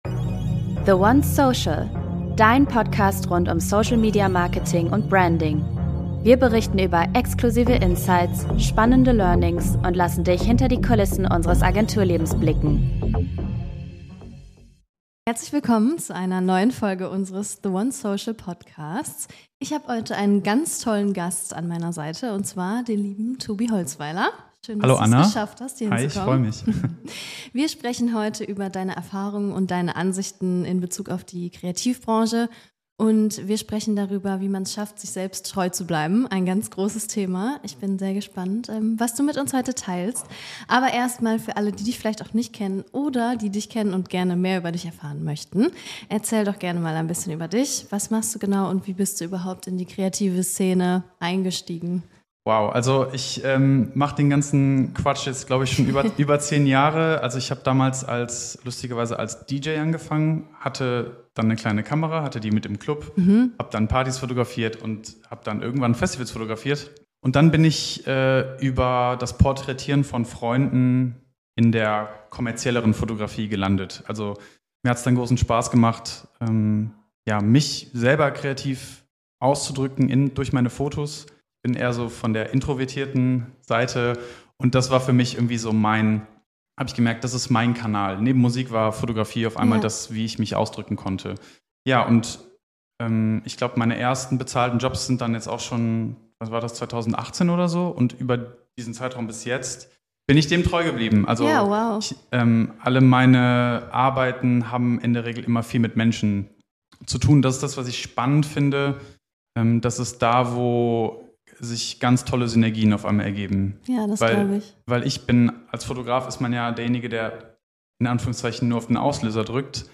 Ein ehrliches Gespräch über kreative Prozesse, Herausforderungen und die Bedeutung von Authentizität in einer digitalisierten Welt.